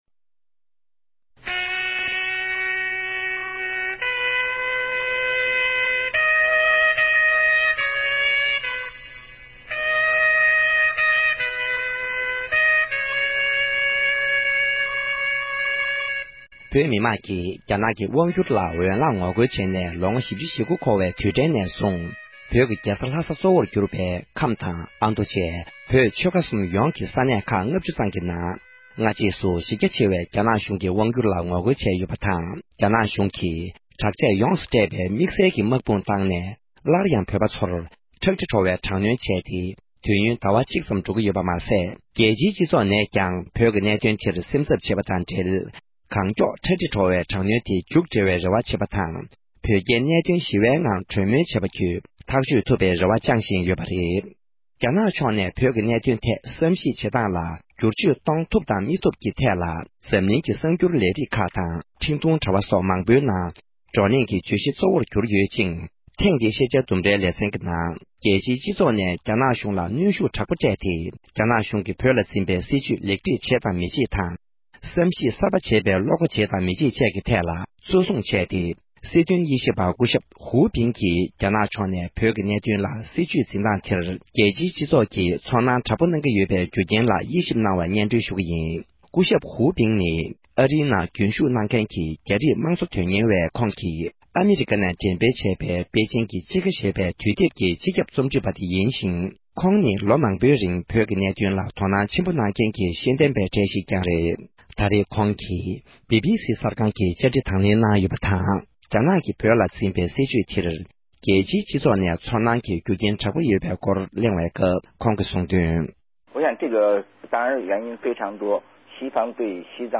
སྦི་སྦི་སི་ གསར་འགྱུར་ལས་ཁང་ནས་བཅའ་འདྲི་གནང་སྐབས་